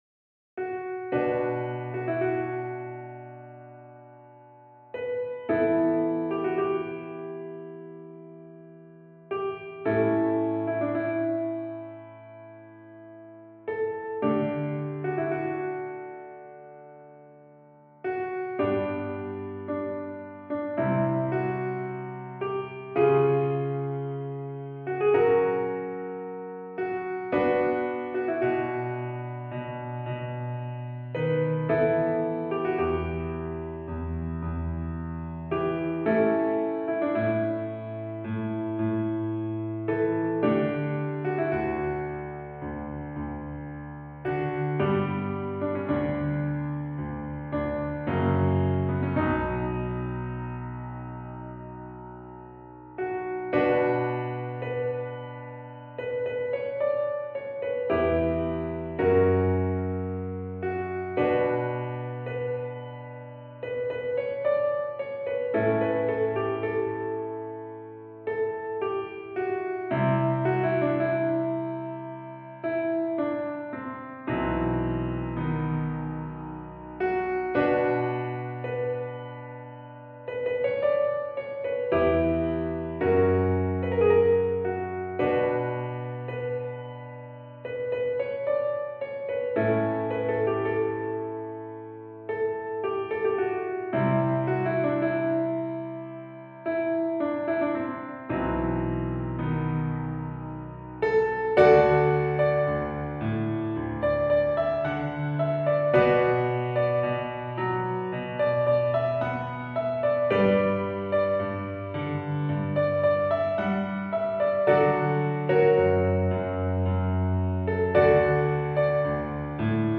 This work is in the style of a spaghetti western.